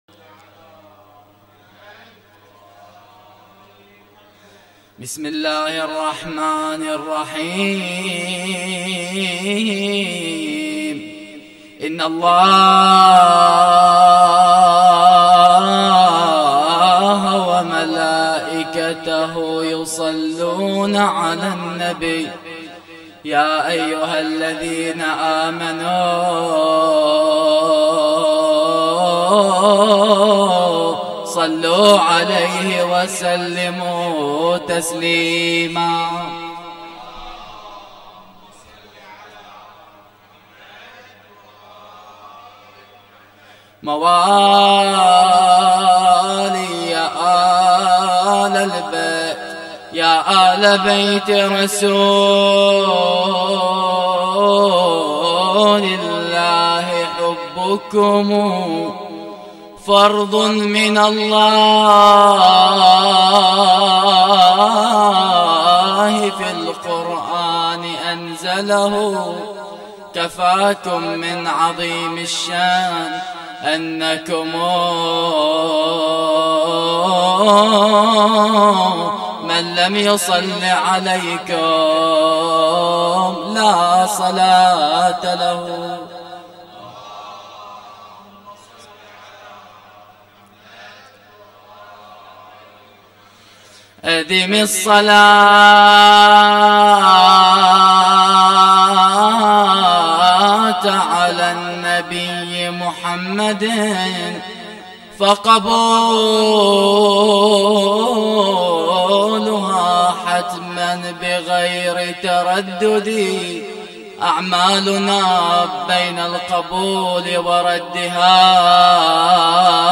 تغطية صوتية: مولد الإمام الهادي ع 1439هـ